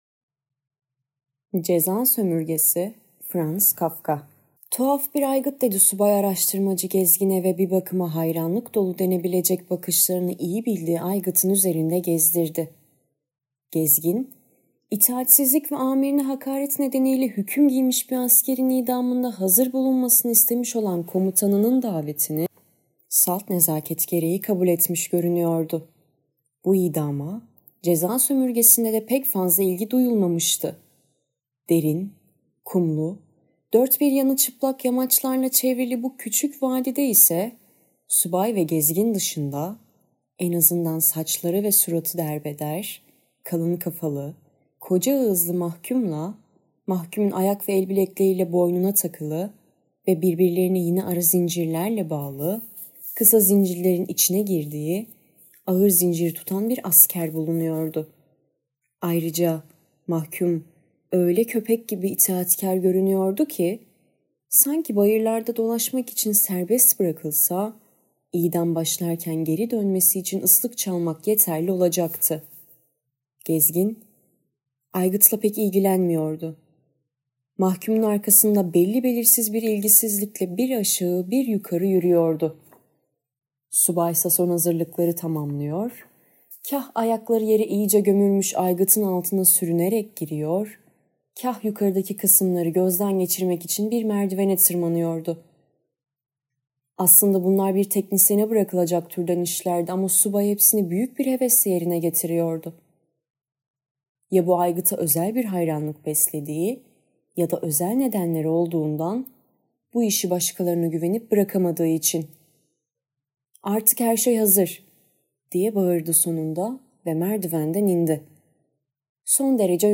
Ceza sömürgesi [Sesli kitap]